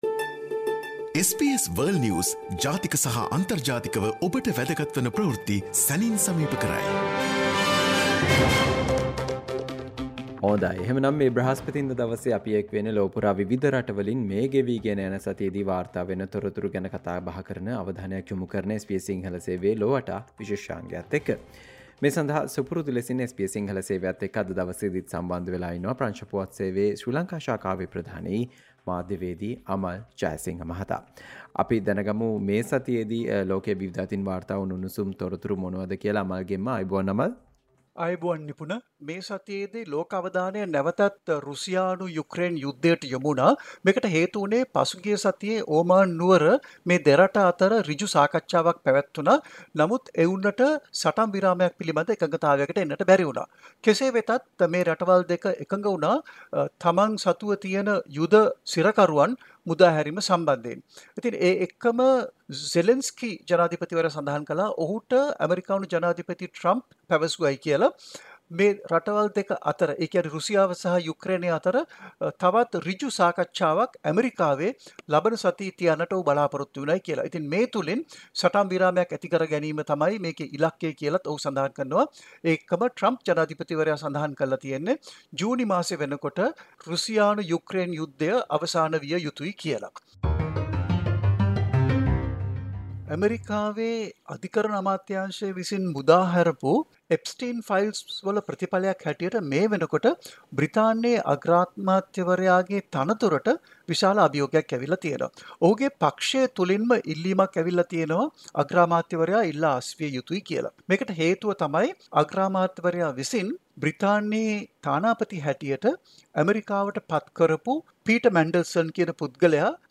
සතියේ විදෙස් පුවත් විග්‍රහය